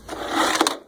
cordInsert.wav